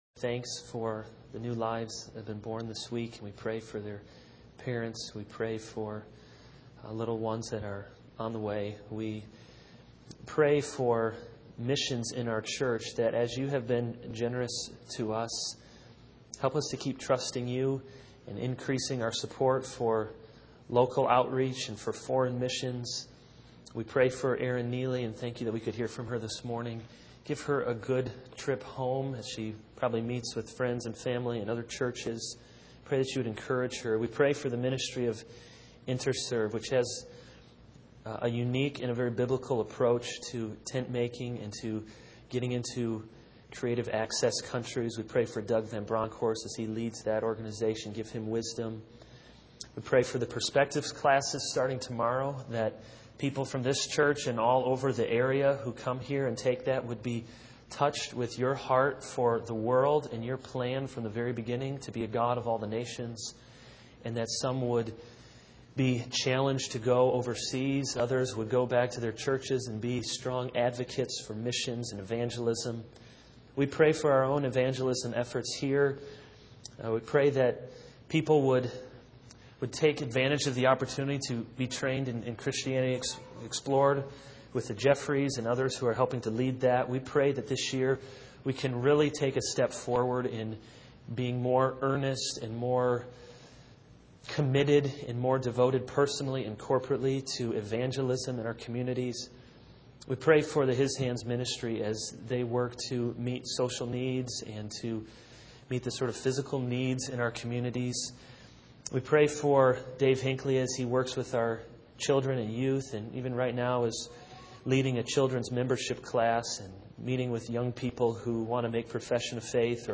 This is a sermon on Exodus 20:1-17 - No other gods.